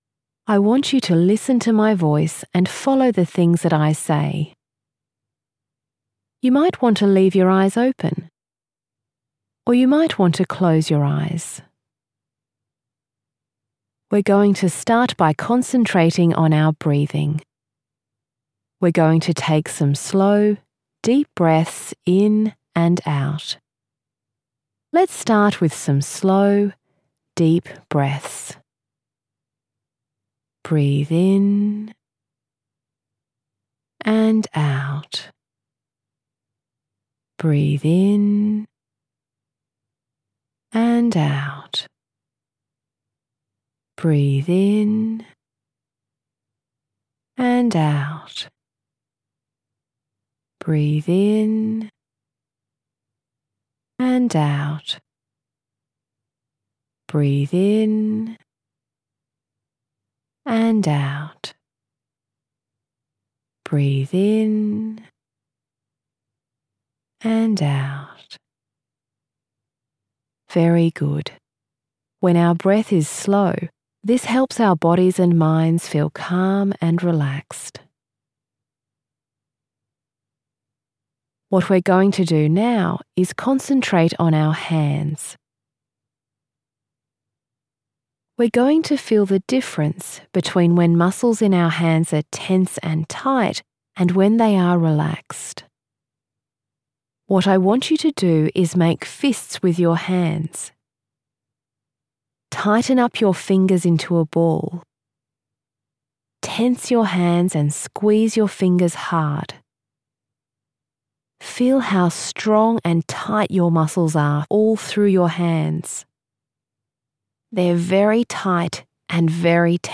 Relaxation exercise